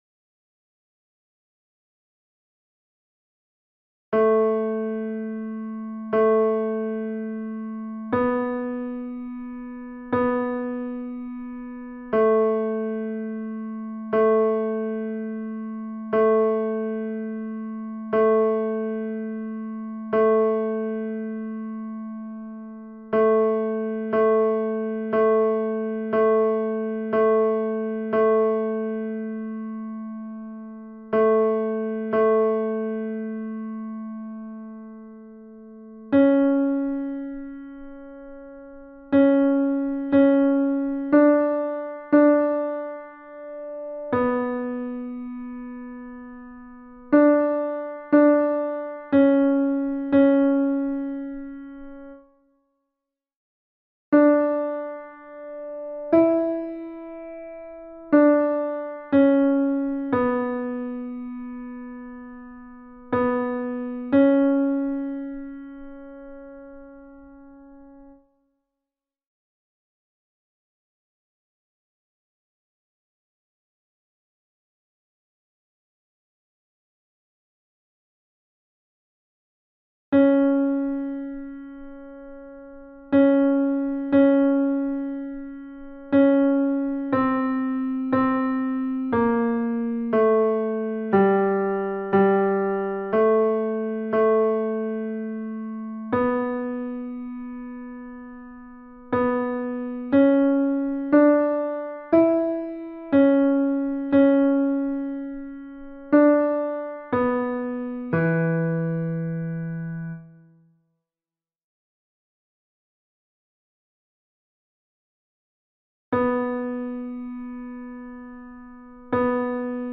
MP3 versions chantées
Tenor (piano)
Ave Verum Corpus Mozart Tenor Mp 3